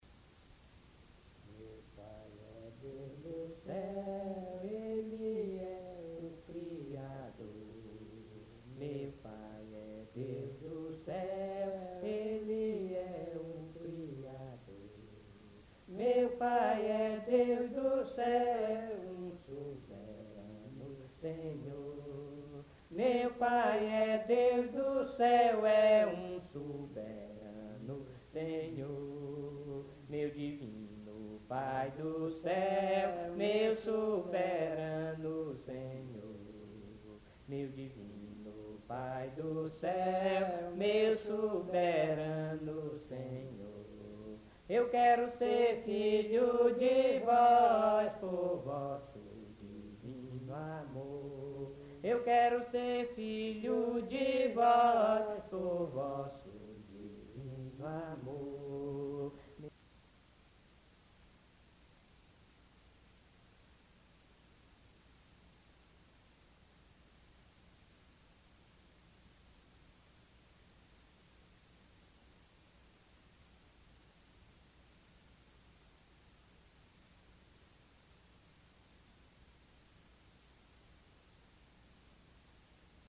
A Capella